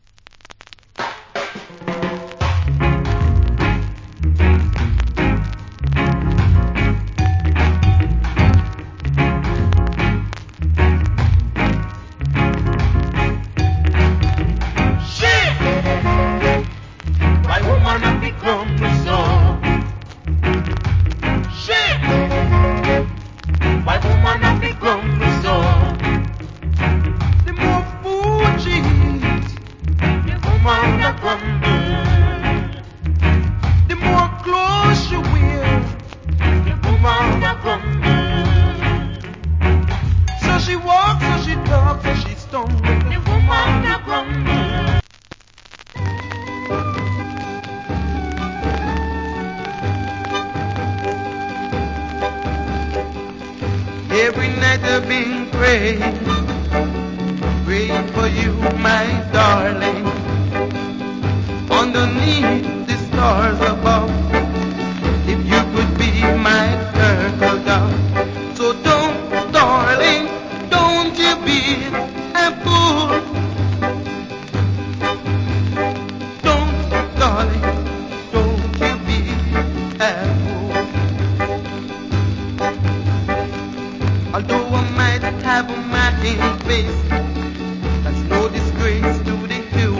Rock Steady Vocal.